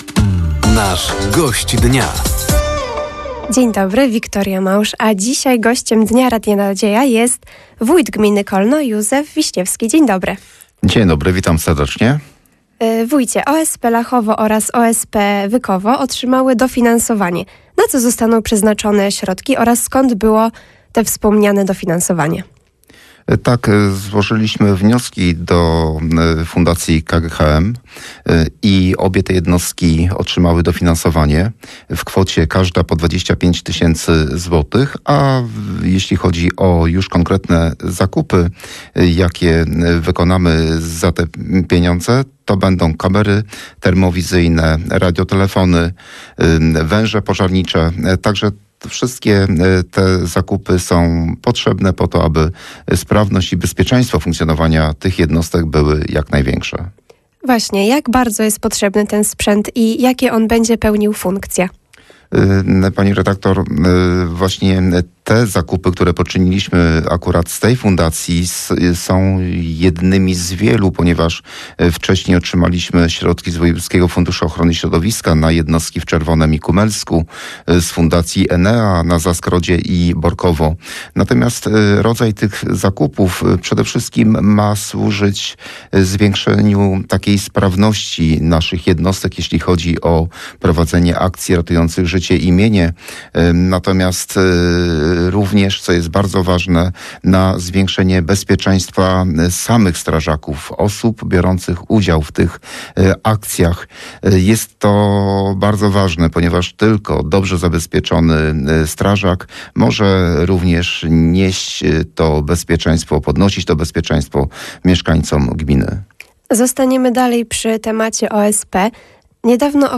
Gościem Dnia Radia Nadzieja był wójt gminy Kolno, Józef Wiśniewski. Tematem rozmowy był między innymi rozwój jednostek OSP, modernizacja dróg gminnych oraz remont budynku szkoły w Glinkach.